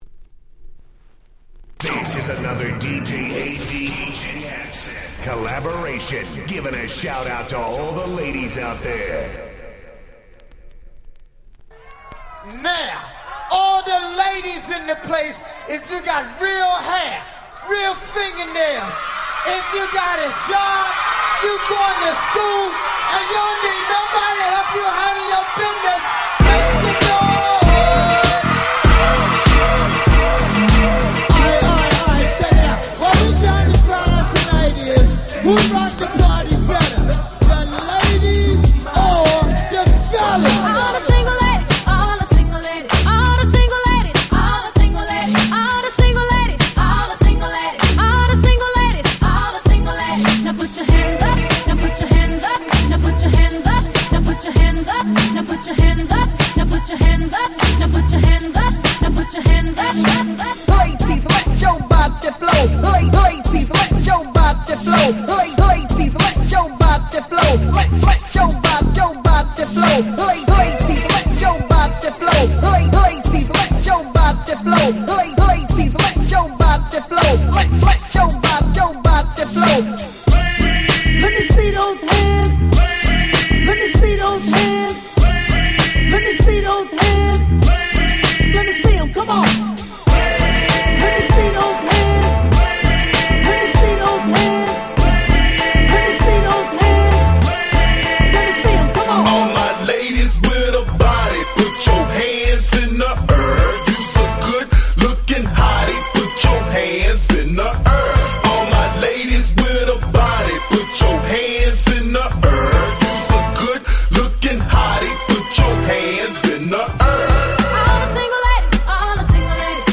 09' Very Nice Remix !!